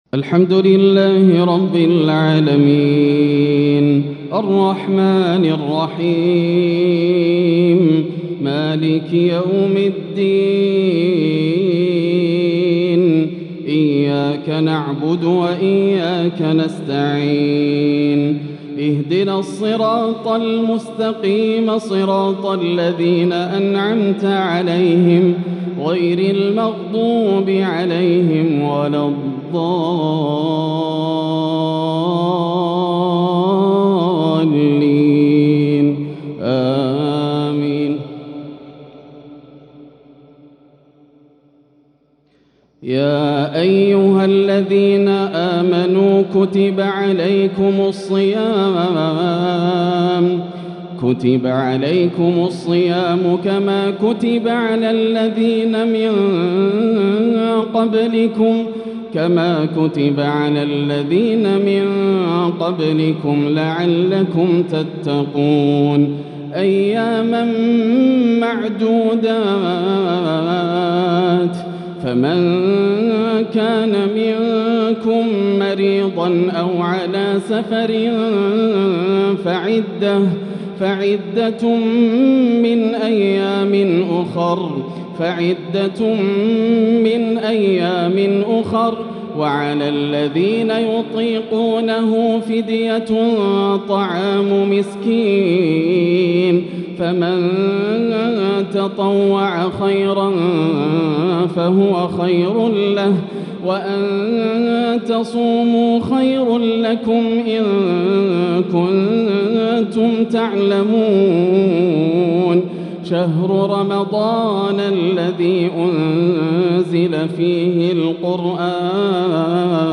(شهر رمضان الذي أنزل فيه القرآن) ترتيل عراقي آسر لآيات الصيام للشيخ ياسر الدوسري | مغرب ٢٩ شعبان ١٤٤٥ > عام 1445 > الفروض - تلاوات ياسر الدوسري